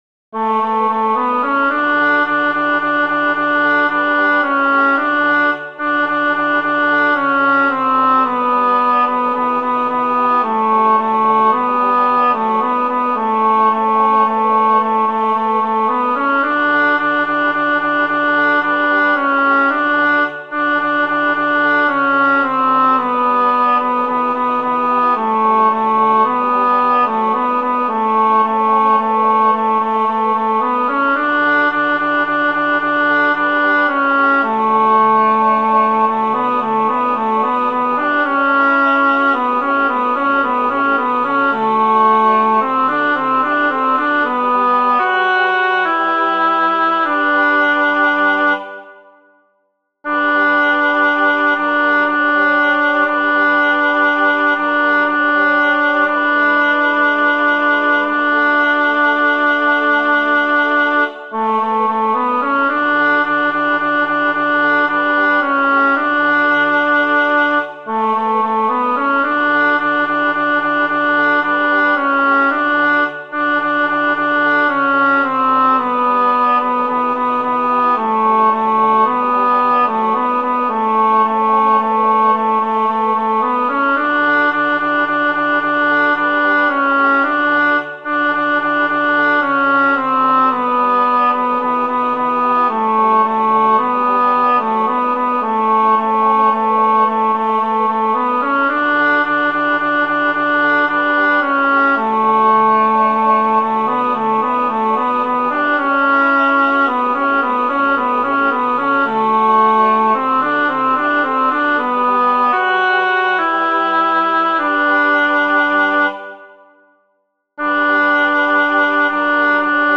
• El tempo indicado es Vivo.
Aquí os dejo los MIDI con las diferentes voces:
Tenores: tenéis una melodía sencilla pero con algunas “trampas” en las que no hay que caer.